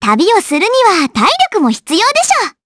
Estelle-Vox_Skill4_jp.wav